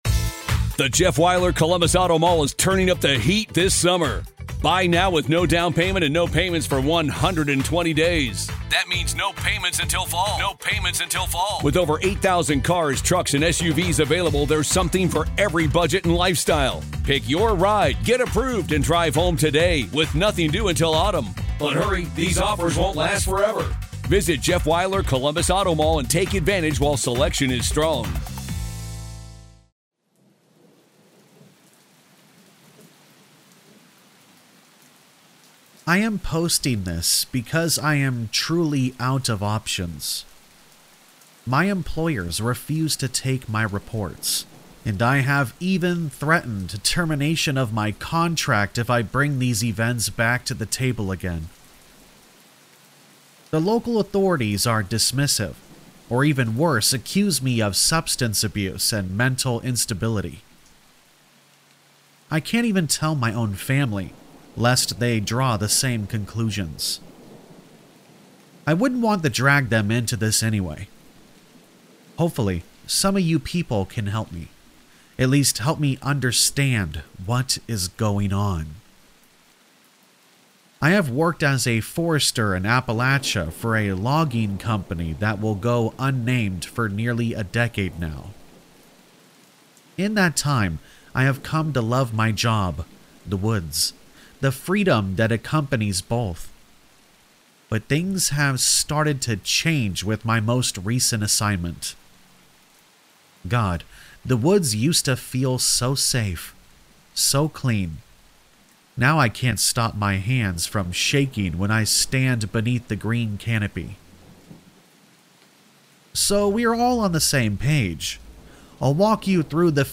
All Stories are read with full permission from the authors: